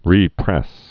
(rēprĕs)